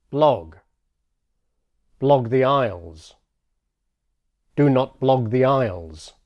• In other words, pay attention to the final consonants of English, and practise the difference between